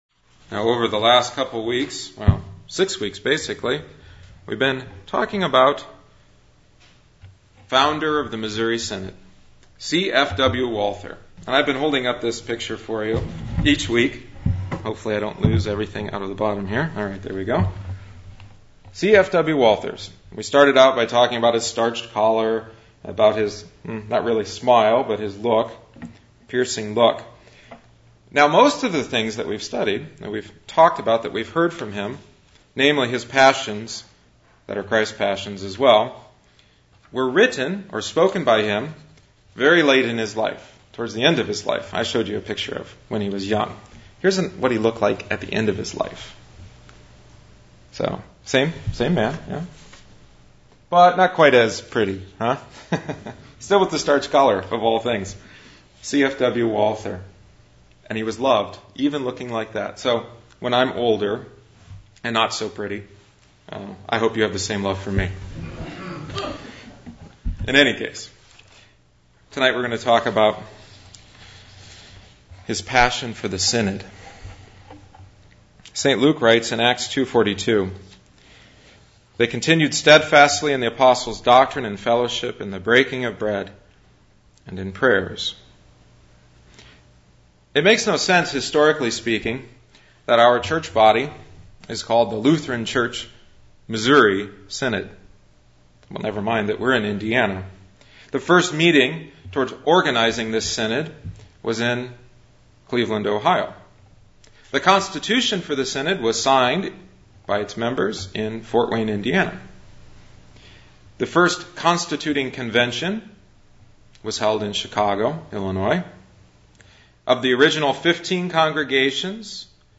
This sermon is the final in the series on Walther’s passions and Christ’s Passion.